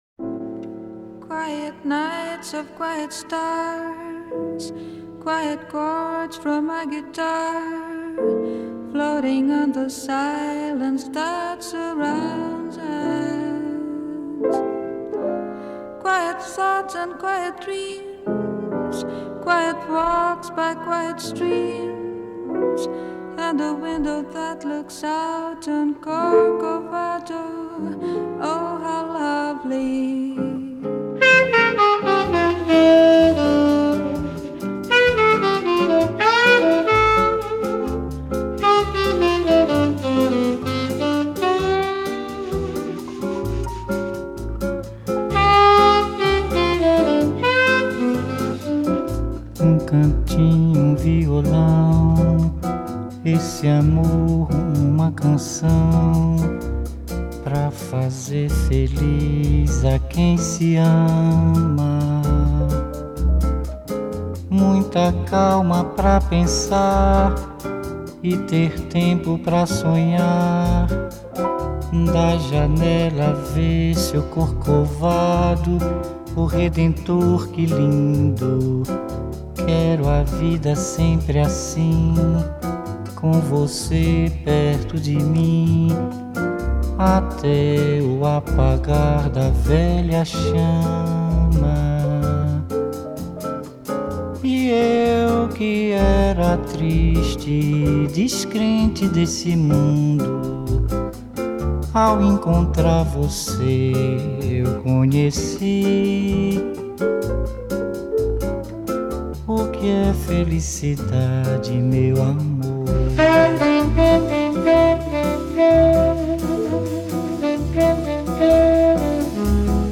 Дуэт увеличился до трио.